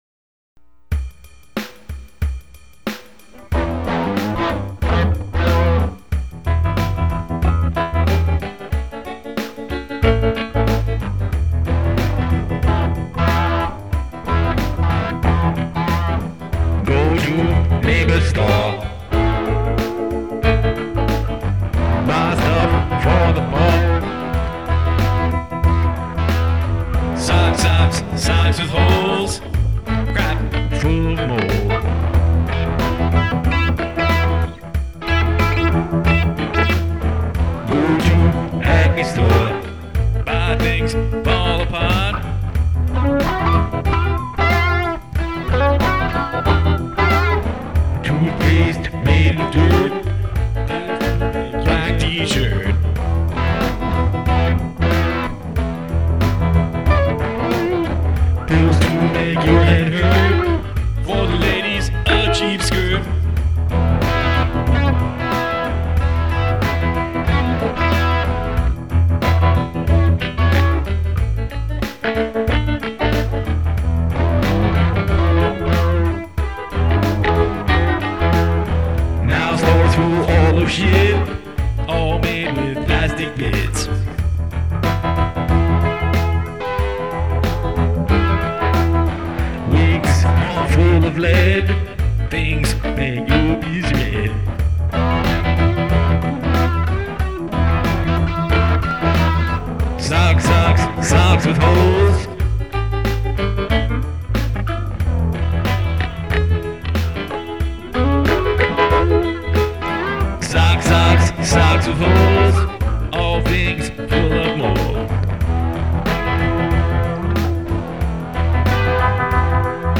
Rock 'n' Roll
Built off a ZZ Top “TV Dinners-esque” sequence.